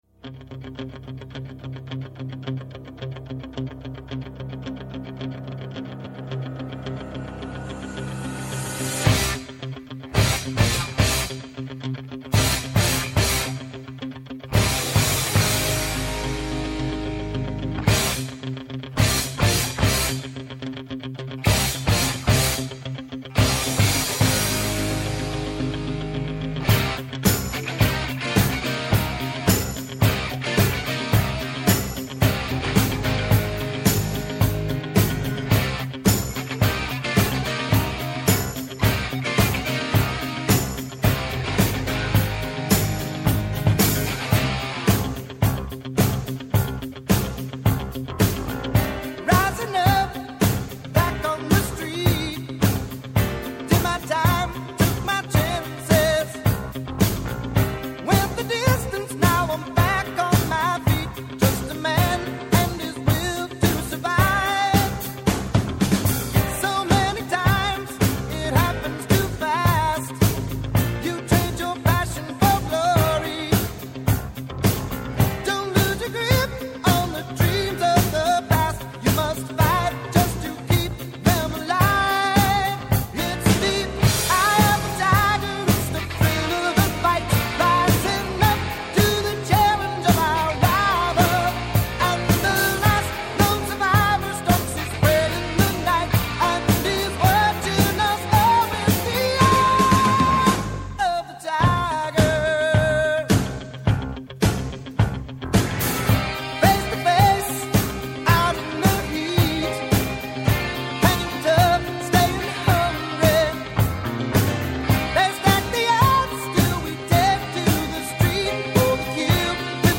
ΠΡΩΤΟ ΚΑΙ ΣΤΑ ΣΠΟΡ, η κοινωνική διάσταση του αθλητισμού, από τις συχνότητες του Πρώτου Προγράμματος της Ελληνικής Ραδιοφωνίας κάθε Σάββατο 13:00-14:00 το μεσημέρι από το Πρώτο Πρόγραμμα.